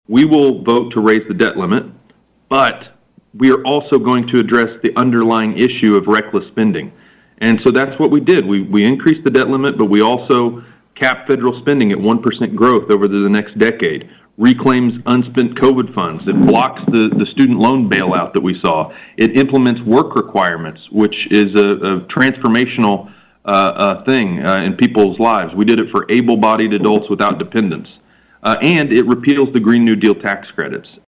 LaTurner appeared on KVOE’s Newsmaker segment Friday, saying it’s now time for the Senate and the White House to make their own moves.